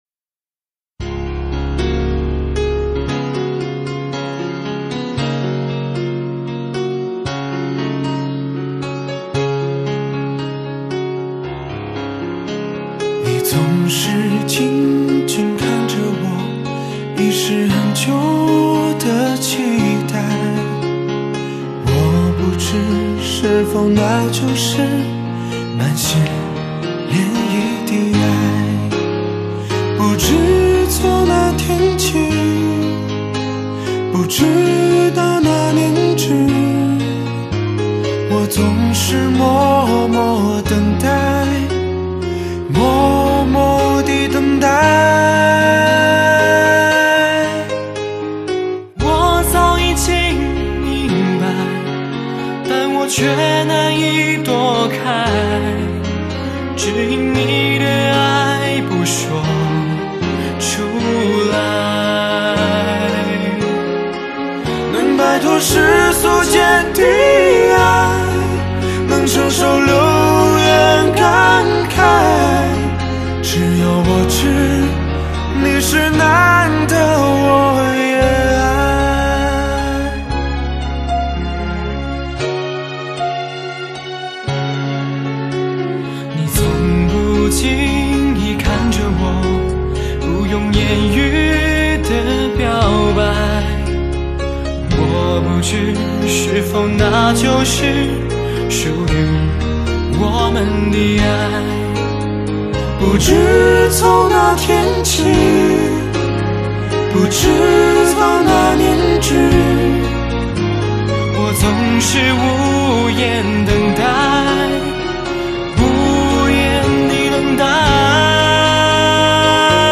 主题曲